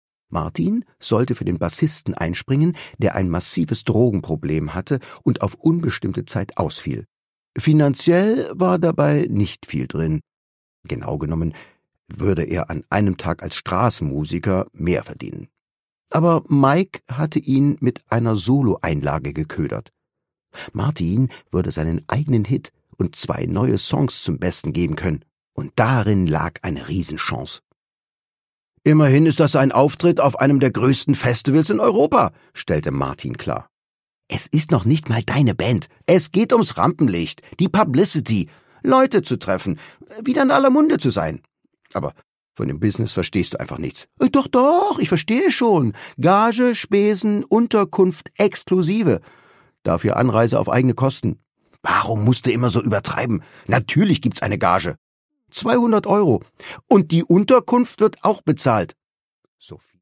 Produkttyp: Hörbuch-Download
Gelesen von: Thomas Nicolai